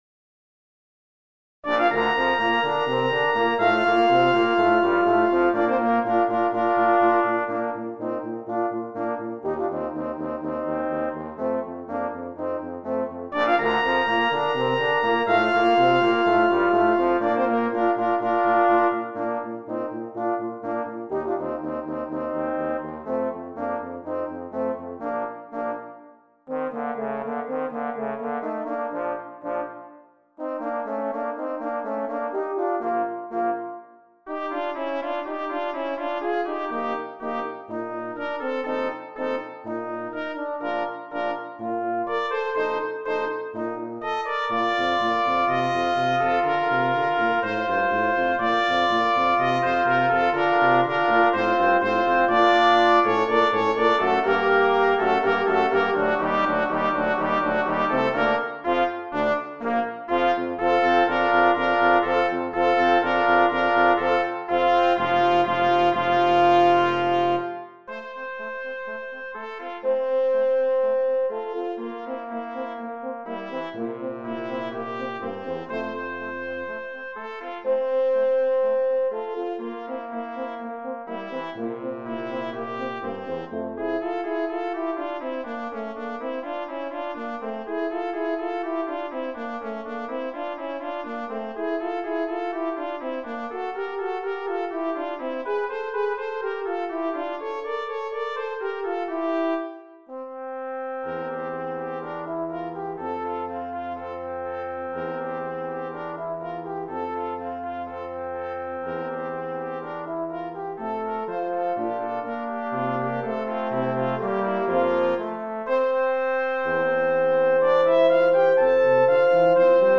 Trompette en Mib, Cor, Trombone et Tuba